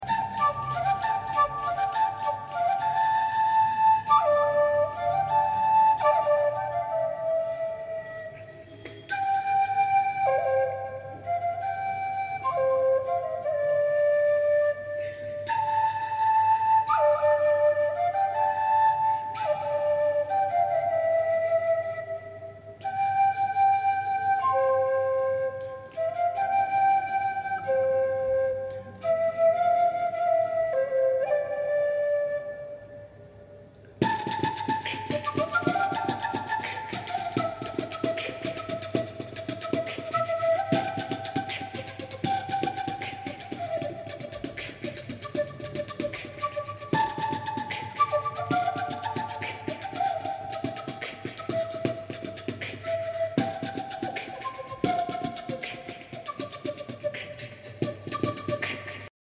flute recorder